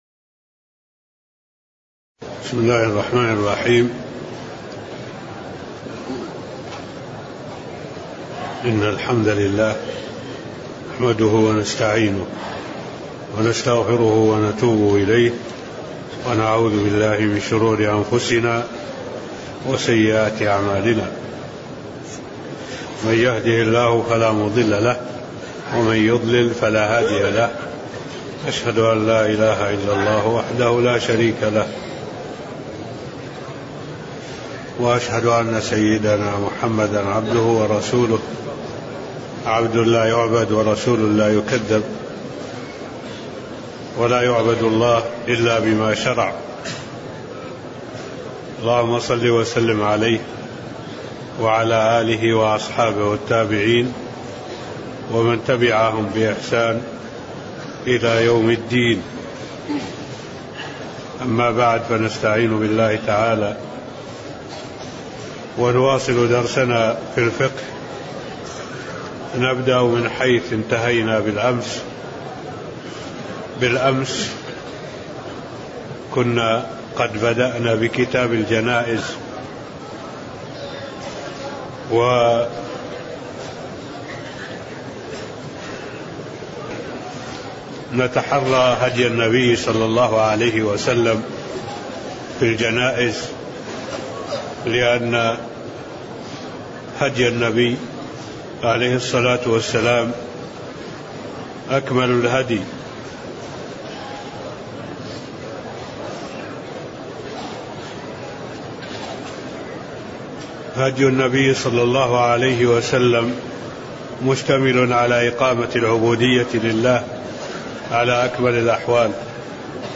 تاريخ النشر ٢٧ ذو الحجة ١٤٢٨ هـ المكان: المسجد النبوي الشيخ: معالي الشيخ الدكتور صالح بن عبد الله العبود معالي الشيخ الدكتور صالح بن عبد الله العبود حكم التداوي (002)